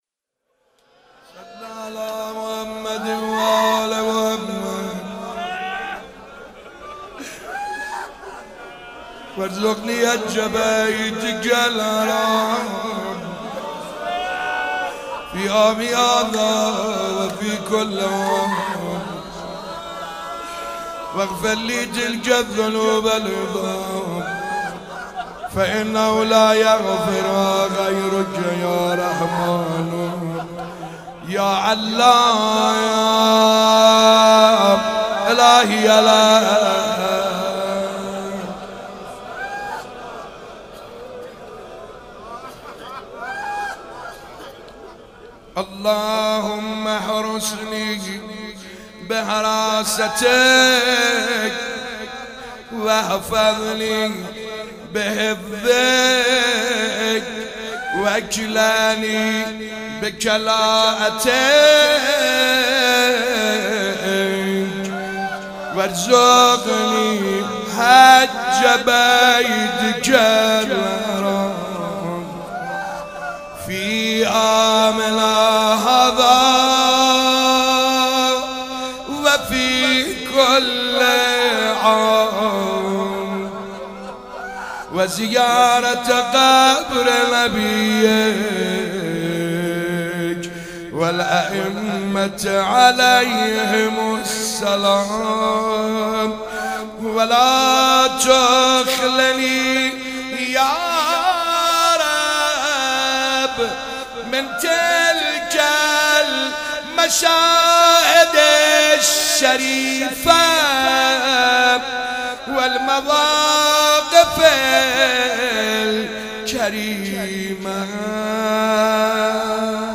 شب 11 ماه مبارک رمضان _دعا خوانی